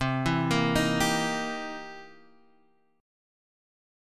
C7#9 chord {x 3 2 3 4 3} chord